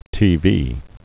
click to hear the word) (w~eb) n.   TV